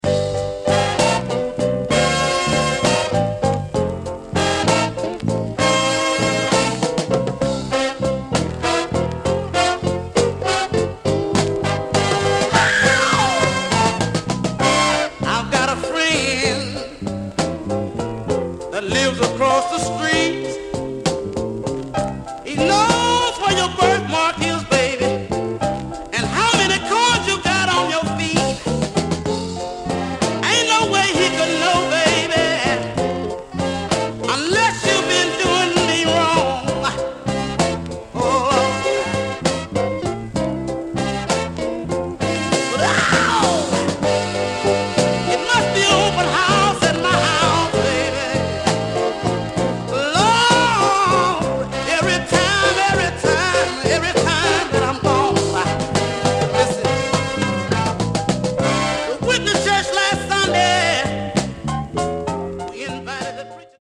digging deep into the blues.